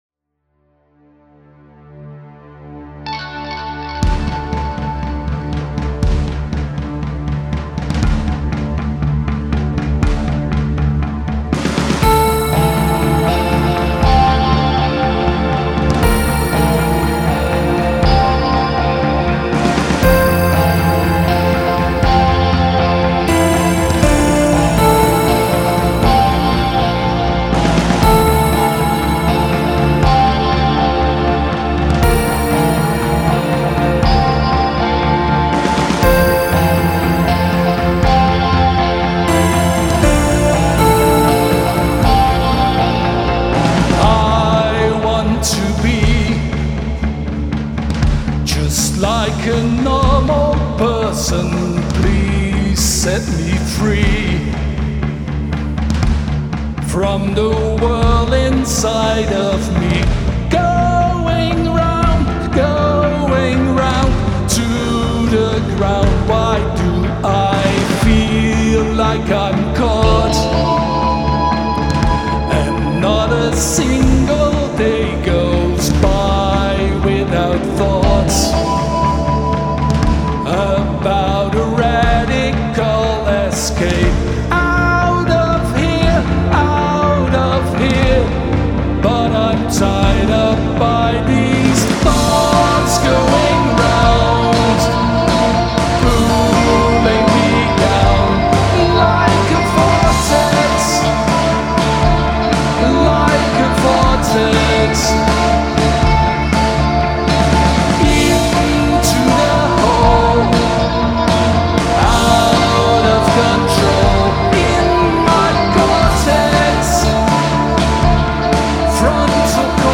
the song is 100% new-wave